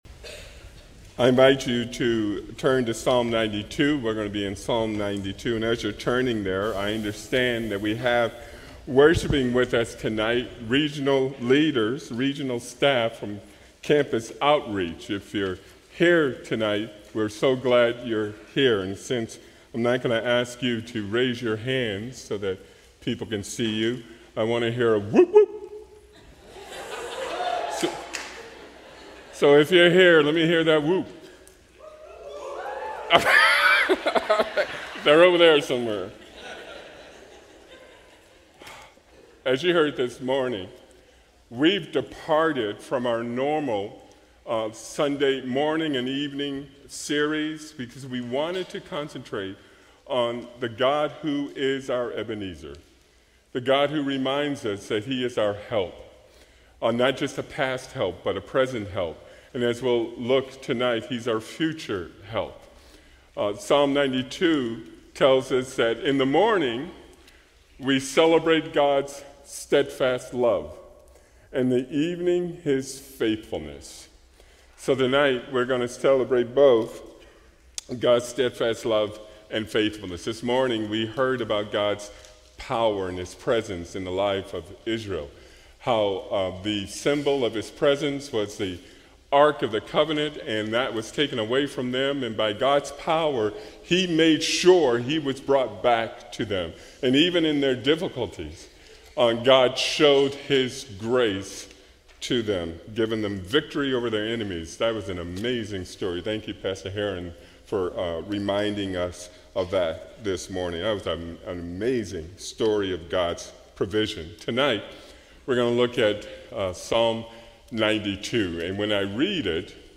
A sermon from the series "Passing on the Faith."